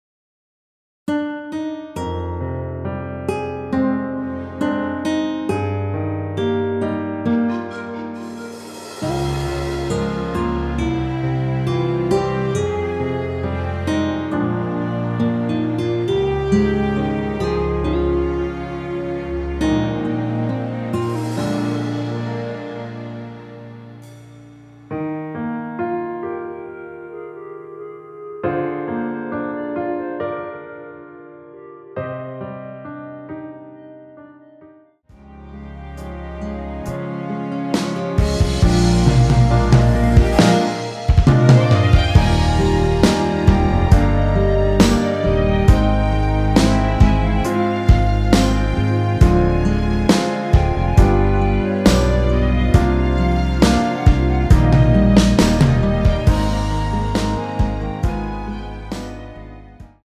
원키에서(-2)내린 멜로디 포함된 MR입니다.(미리듣기 확인)
Eb
앞부분30초, 뒷부분30초씩 편집해서 올려 드리고 있습니다.
중간에 음이 끈어지고 다시 나오는 이유는